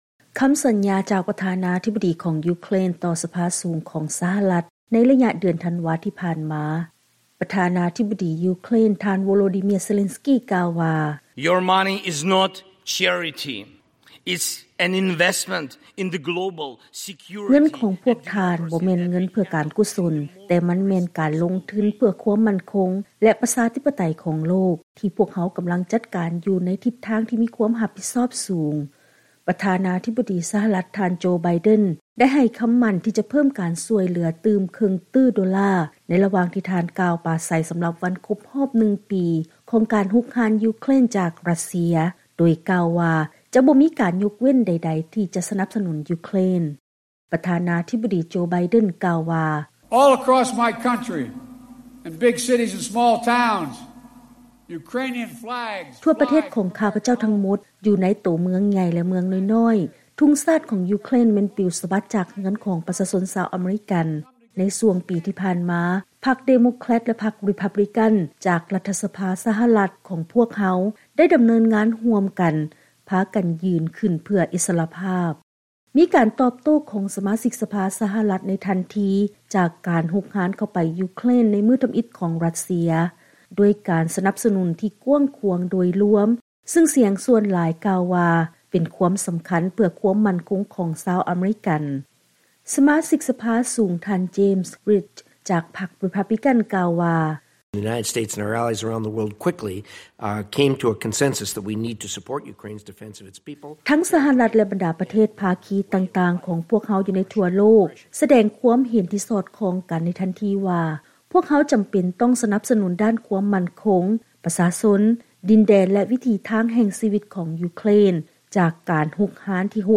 ເຊີນຮັບຟັງລາຍງານກ່ຽວກັບ ຄວາມມຸ່ງໝັ້ນຂອງສະຫະລັດໃນການໃຫ້ຄວາມຊ່ວຍເຫຼືອແກ່ ຢູເຄຣນ ຫຼາຍທີ່ສຸດນັບຕັ້ງແຕ່ສົງຄາມສະຫະລັດຢູ່ໃນ ອີຣັກ